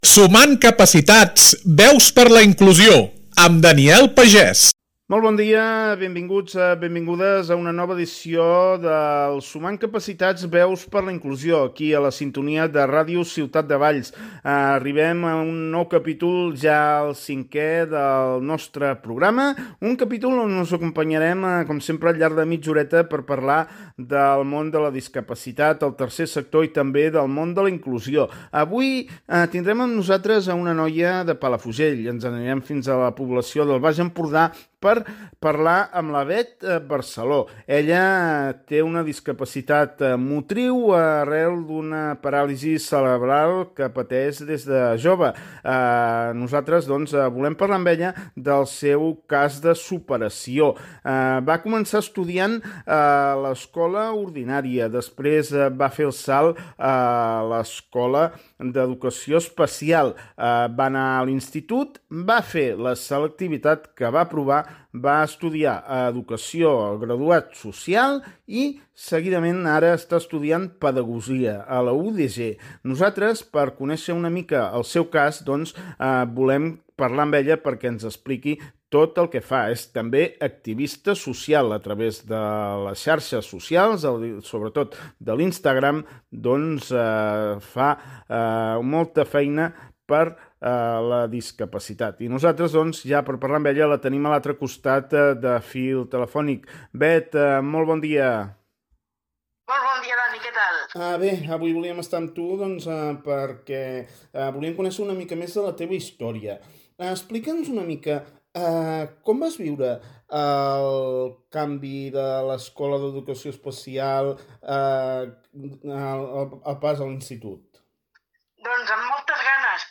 Un espai d’entrevistes sobre el món de la discapacitat, la inclusió i el Tercer Sector.